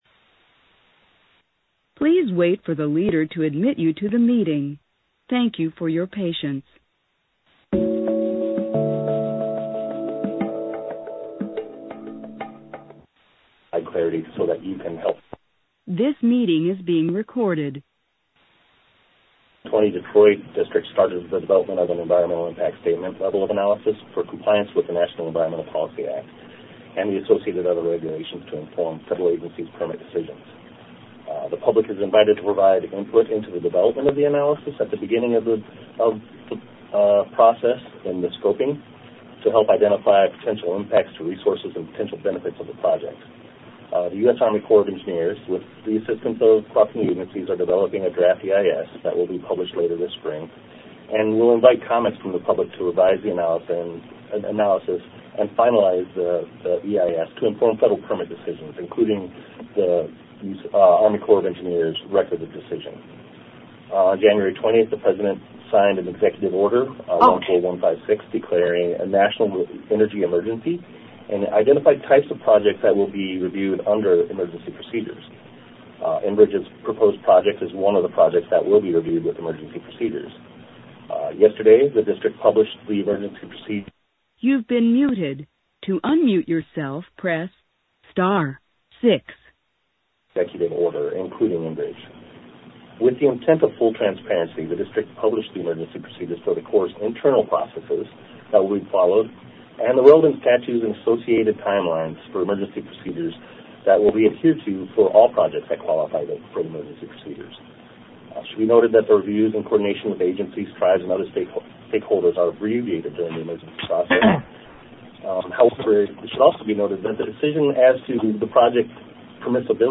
CLICK BELOW TO HEAR WEDNESDAY’S ARMY CORPS NEWS CONFERENCE